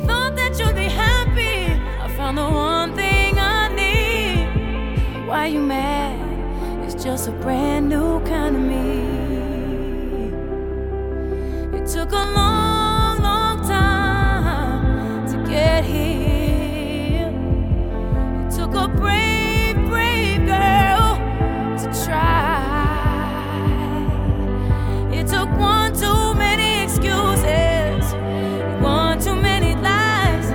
en mêlant des sons électriques à des mélodies reggae et soul
R&B Soul